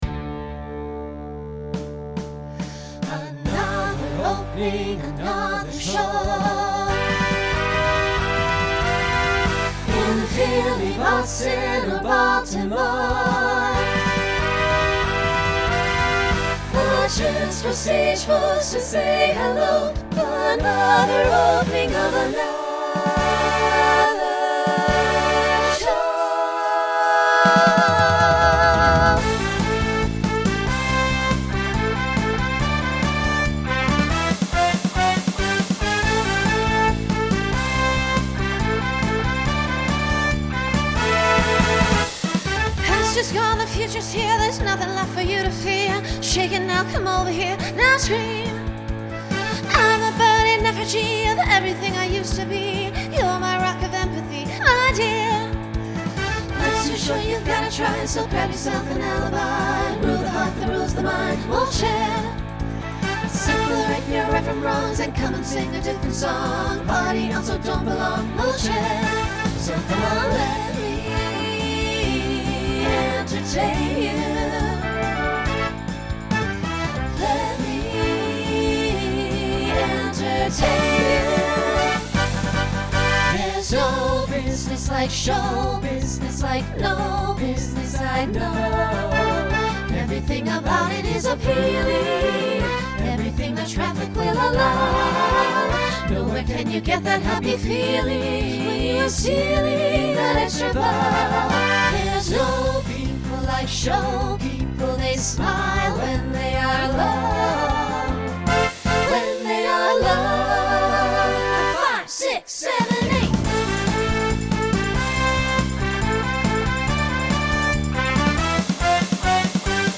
Broadway/Film , Pop/Dance Instrumental combo
Voicing SAB